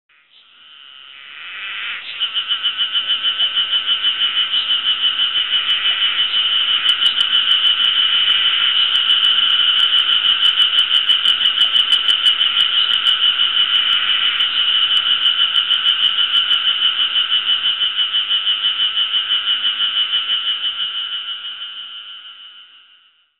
やがて、薄曇りの空が晴れて日が差し出すと、突然エゾハルゼミの大合唱が始まり、びっくりしました。